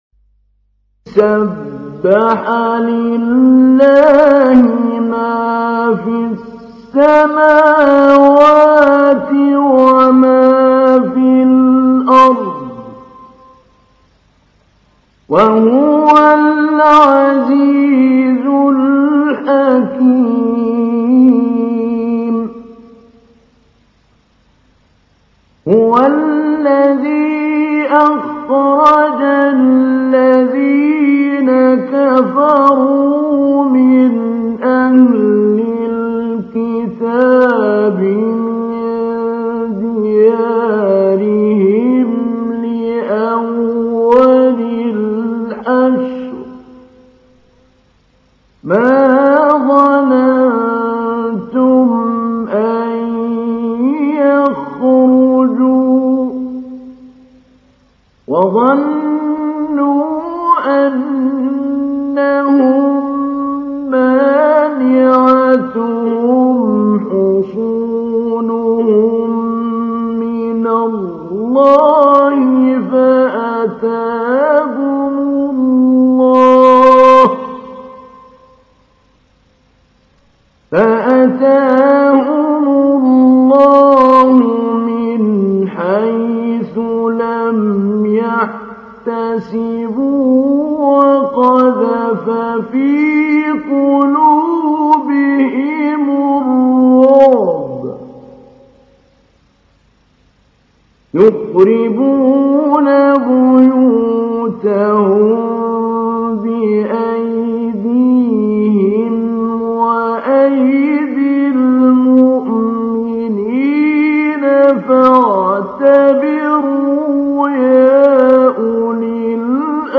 تحميل : 59. سورة الحشر / القارئ محمود علي البنا / القرآن الكريم / موقع يا حسين